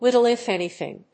アクセントlíttle if ánything